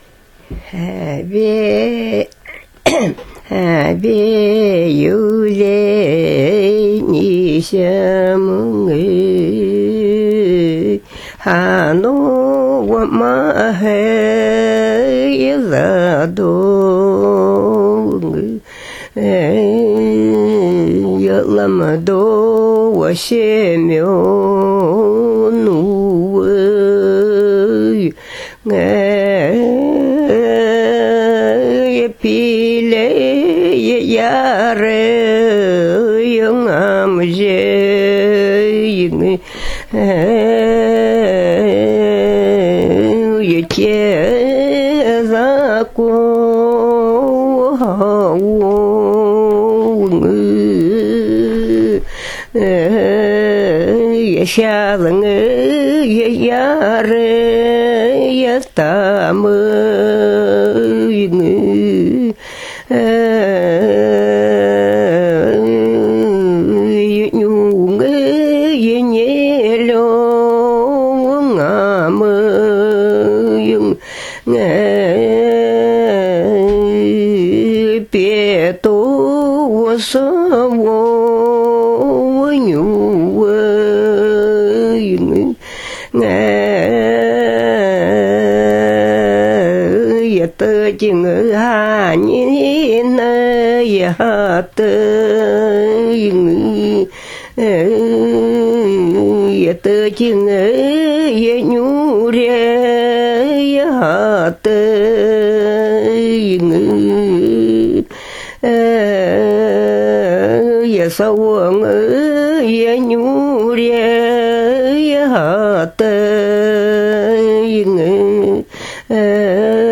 Фрагмент эпической песни жанра ярăбц . Эпическая песня исполняется на канинском (крайнезападном) диалекте тундрового ненецкого языка.